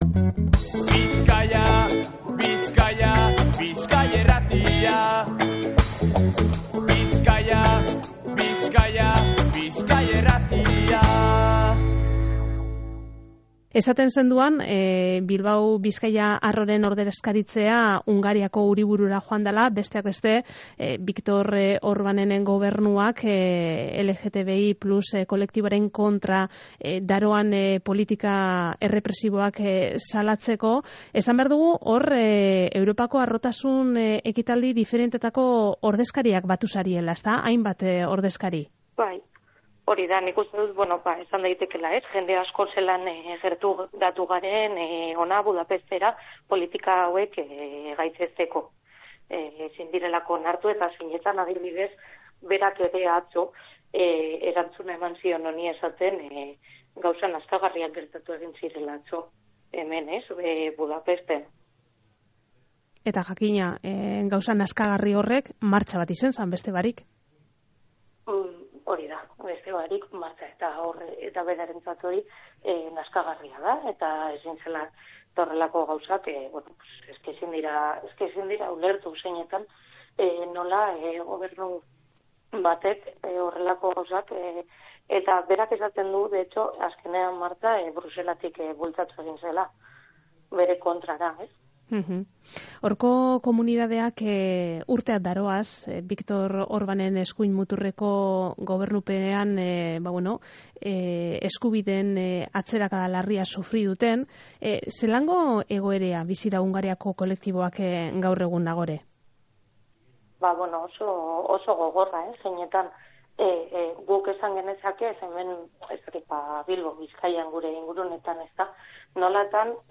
Budapestetik zuzen-zuzenean.